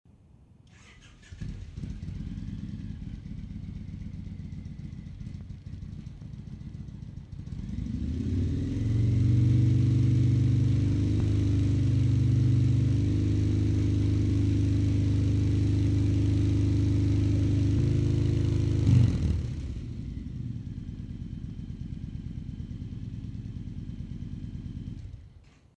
Everything was recorded at exactly the same level.
Stock Vance & Hines packing w stretched baffle (mellower)
61 db idle 79 db 3000 rpm